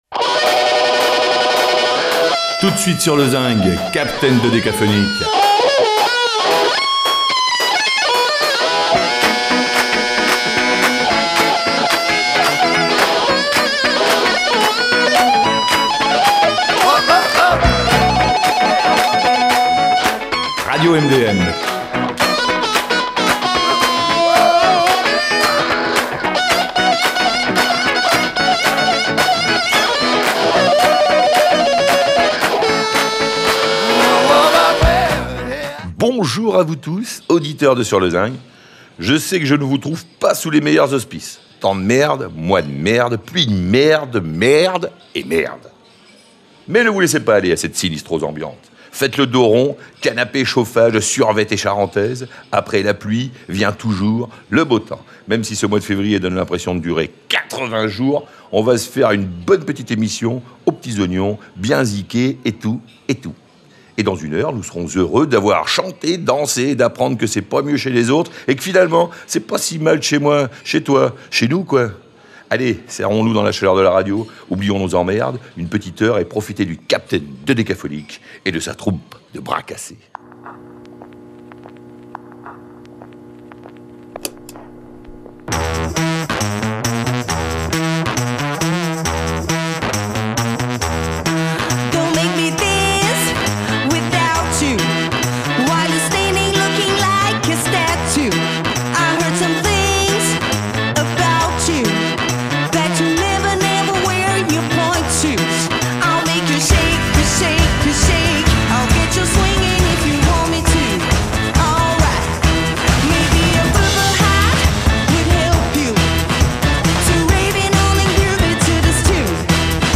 La chanson et le rock français d’aujourd’hui, les artistes locaux et les musiques du monde, dans l’esprit du Festival de Luxey.